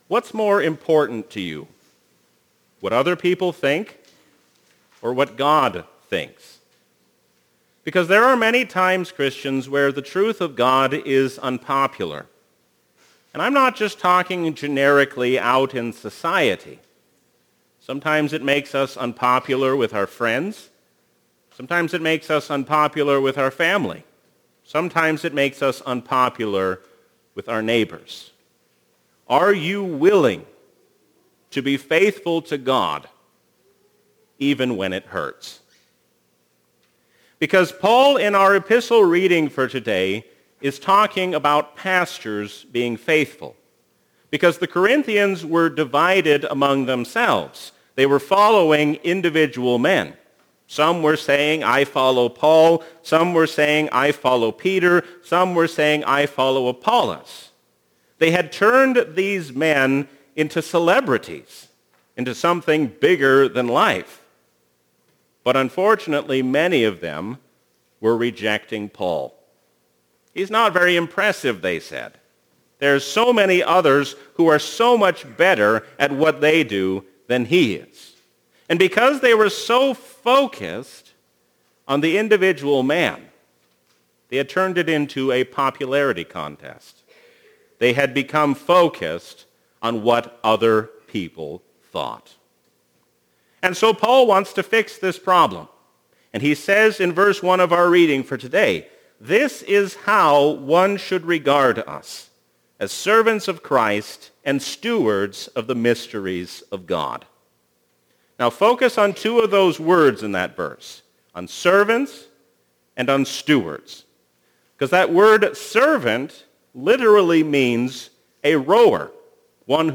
A sermon from the season "Trinity 2024." Let us not be double minded, but single minded in our devotion toward God.